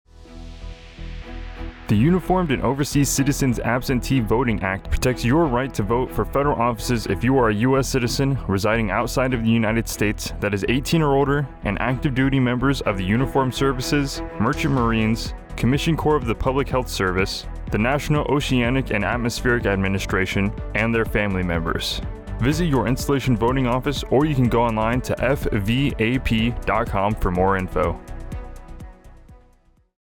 Radio Spot - Voting Overseas